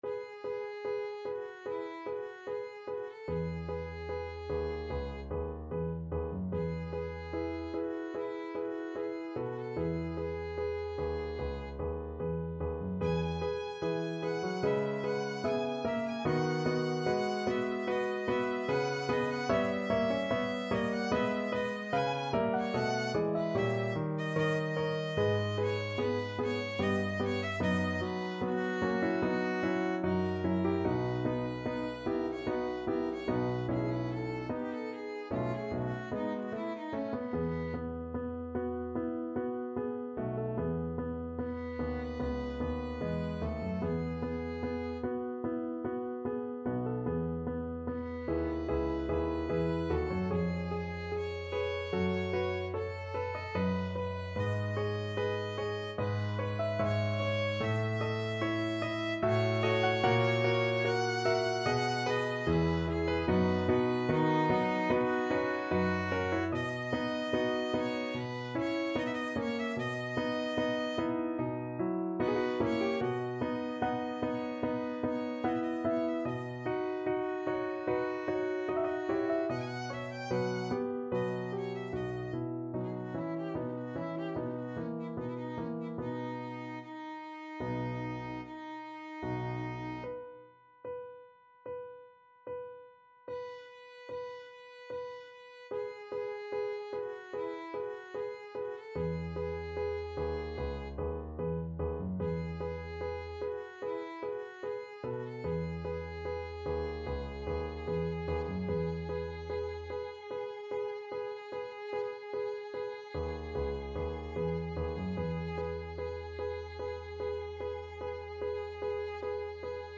Classical Dvořák, Antonín Serenade for Strings, Op. 22, First Movement Violin version
Violin
4/4 (View more 4/4 Music)
B4-Ab6
E major (Sounding Pitch) (View more E major Music for Violin )
~ = 74 Moderato
Classical (View more Classical Violin Music)